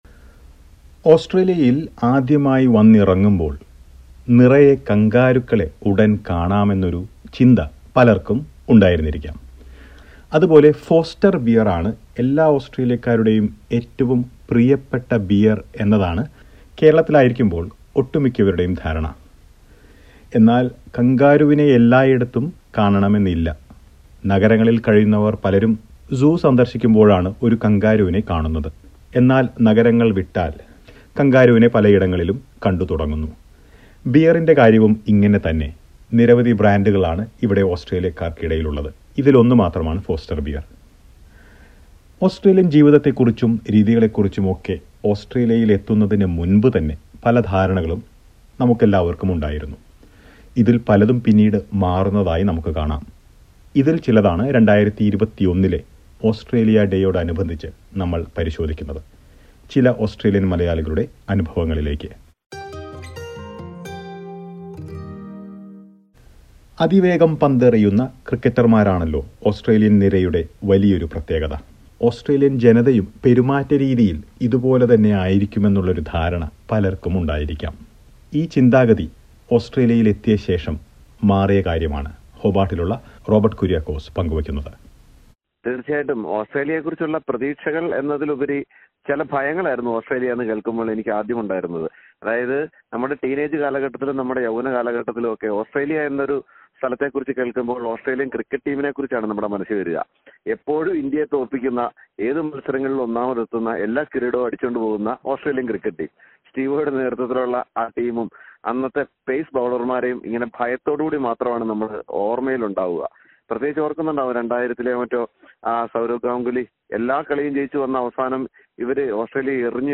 Every migrant has a picture of Australia before reaching this land. But many of the perceptions change over time. Listen to a report about this on this Australia Day.